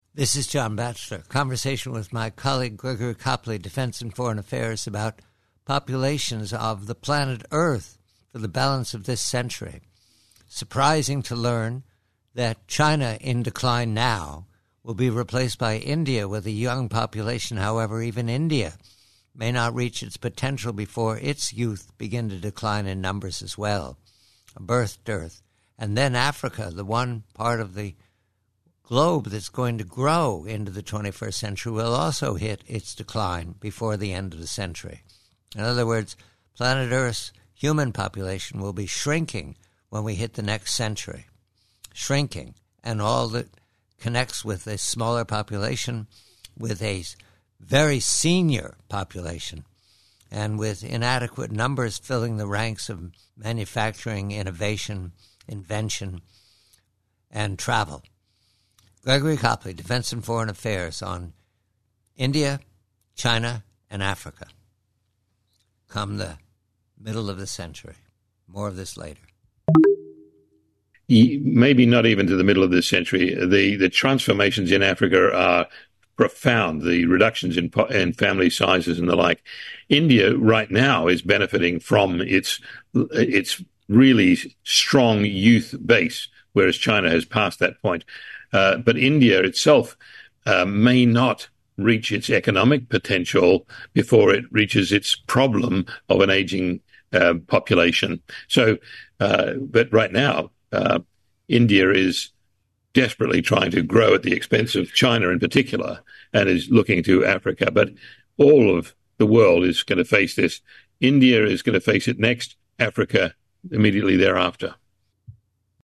PREVIEW: GLOBAL POPULATION 2100: Conversation